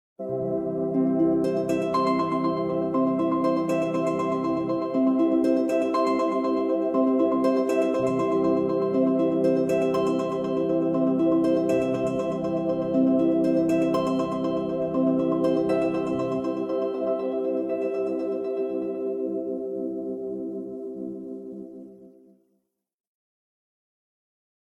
Index of /phonetones/unzipped/Sony/Xperia-sola/alarms